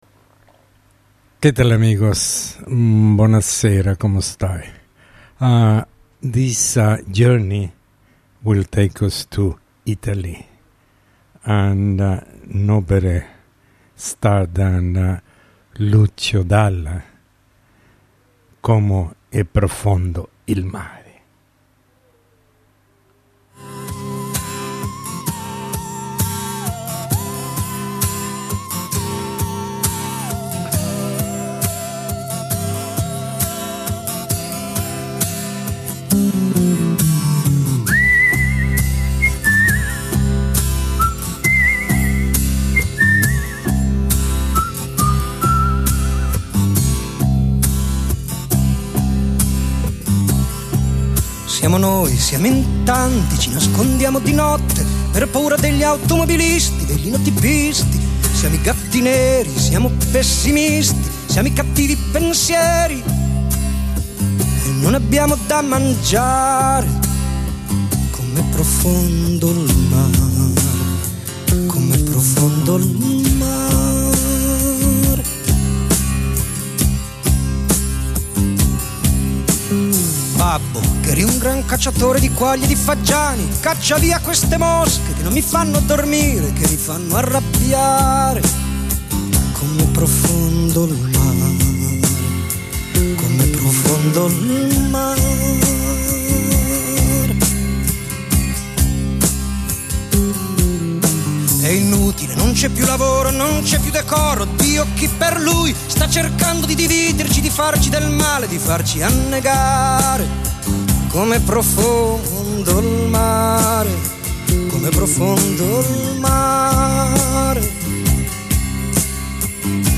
Italian Pop Music